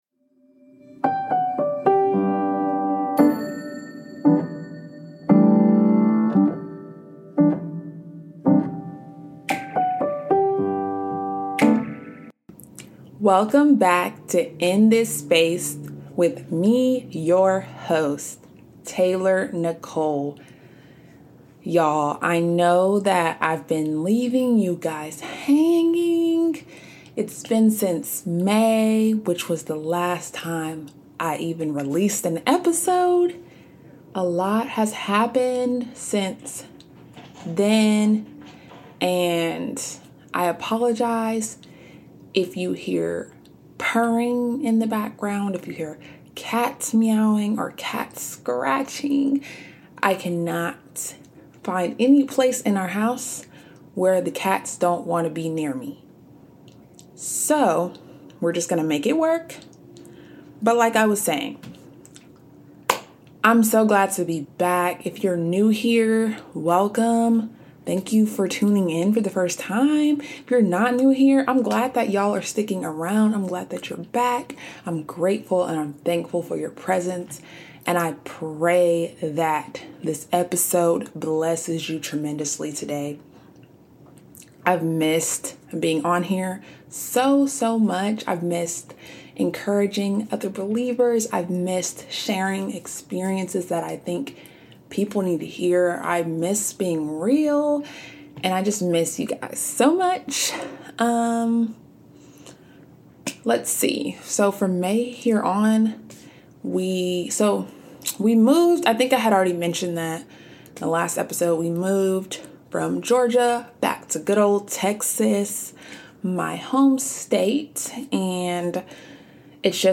P.S. my cats interrupted me several time, but can't stop won't stop